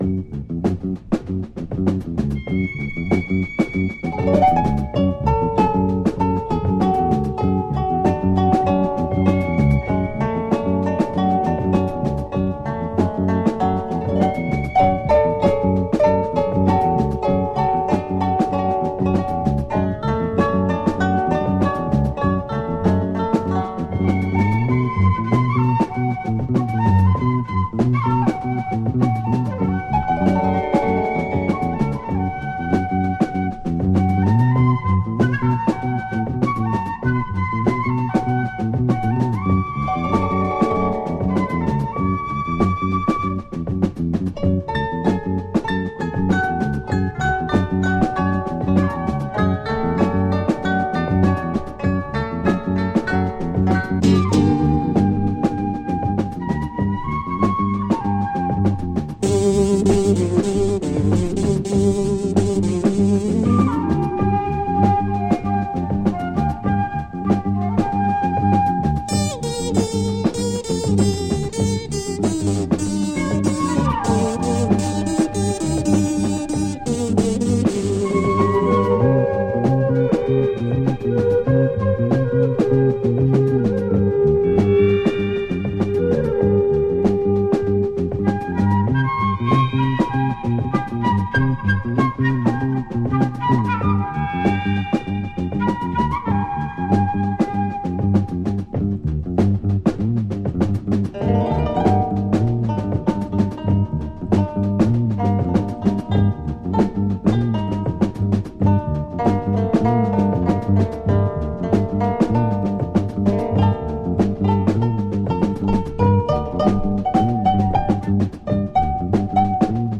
Killer Asian beats